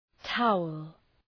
Προφορά
{‘taʋəl}